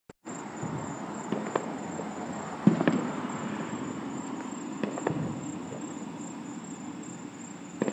Crickets and Fireworks
crickets-and-fireworks.m4a